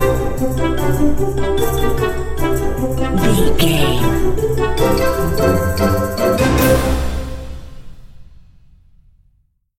Uplifting
Lydian
flute
oboe
strings
orchestra
cello
double bass
percussion
silly
circus
goofy
comical
cheerful
perky
Light hearted
quirky